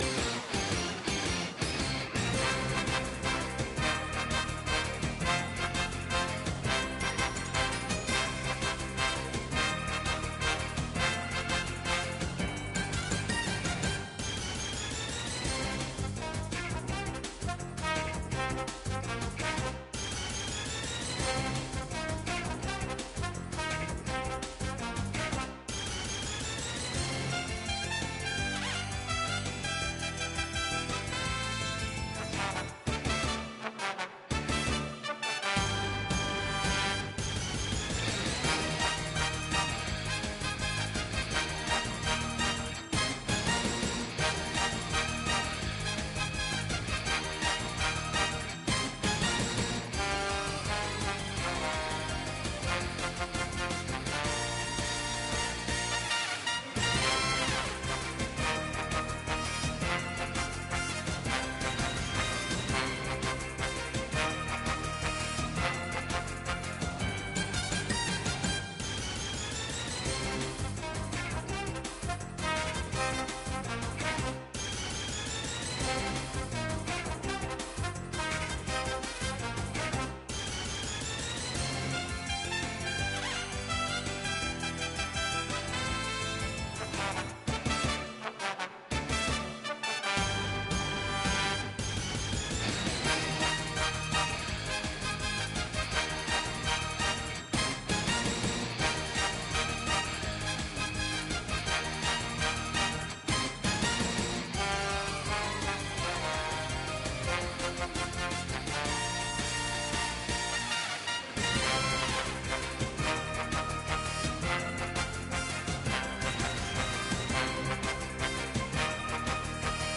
语音文件